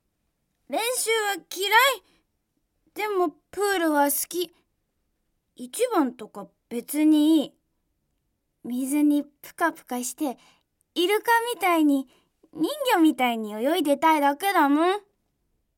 セリフA
ボイスサンプル